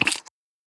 SLime sounds
slime_step_1.ogg